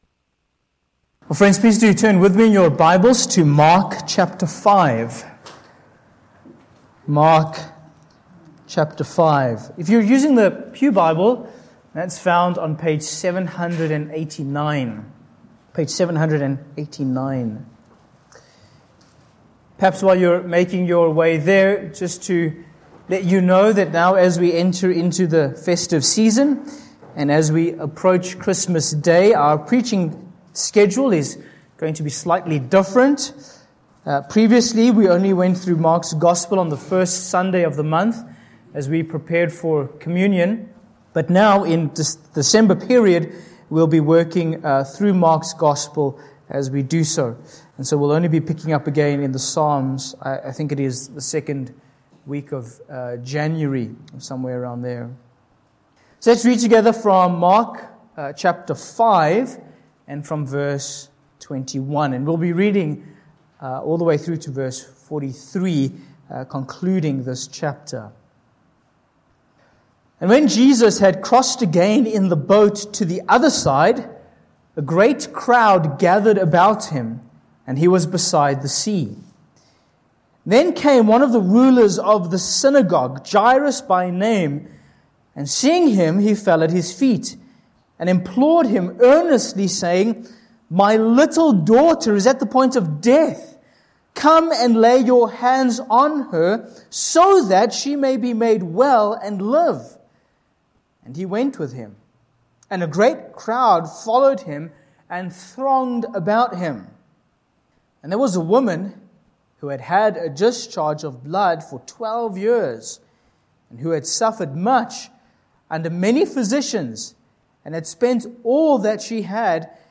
Sermon Points: 1. Scene 1: Jairus Comes to Jesus v21–24